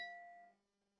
Glass Clink
Two glass vessels clinking together in a toast with a bright, clear ring
glass-clink.mp3